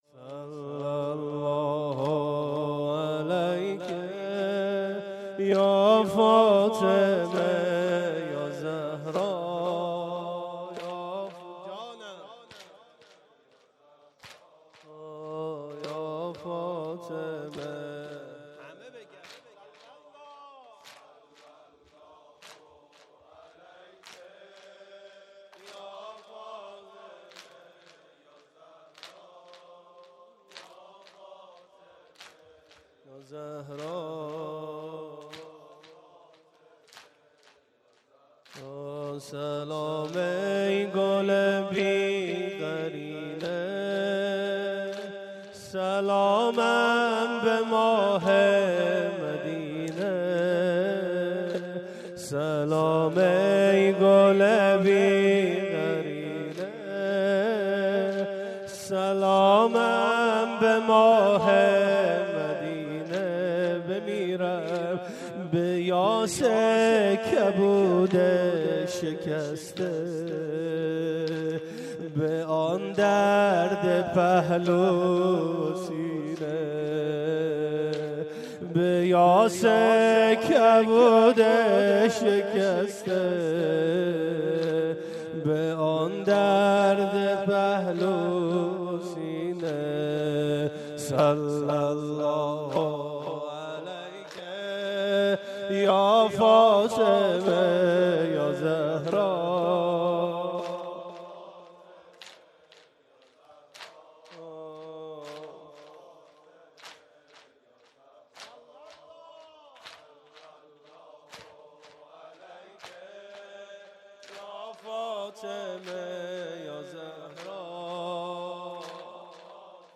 شب اول فاطميه 95 - هيئت مصباح الهدی - صلی الله عليك